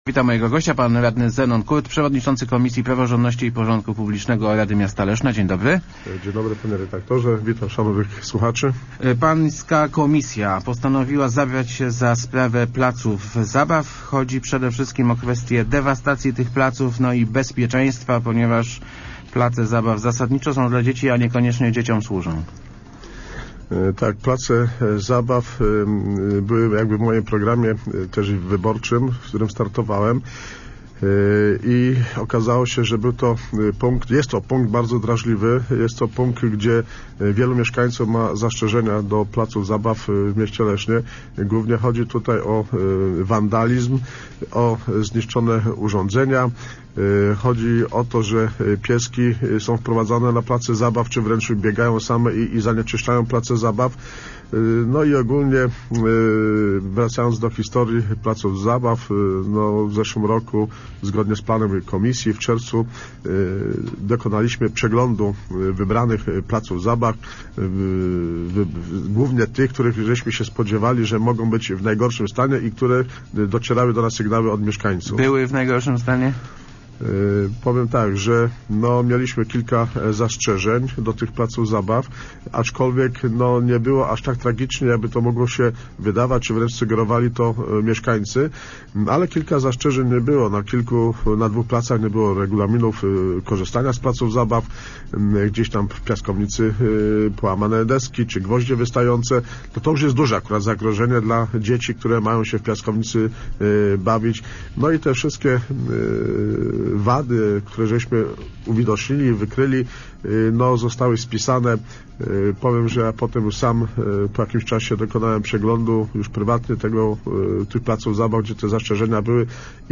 Regulaminy okre�laj�ce zasady u�ywania placów zabaw w Lesznie s� nieskuteczne – mówi� w Rozmowach Elki Zenon Kurt, przewodnicz�cy Komisji Praworz�dno�ci i Porz�dku Rady Miasta Leszna. Komisja opracowa�a projekt nowego regulaminu, który ma obowi�zywa� i na miejskich, i na osiedlowych placach zabaw.